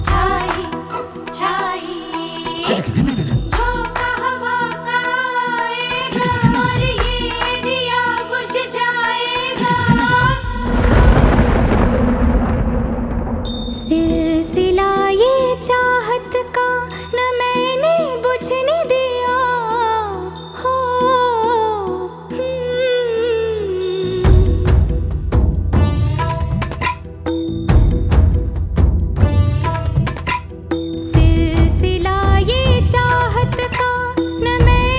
# Bollywood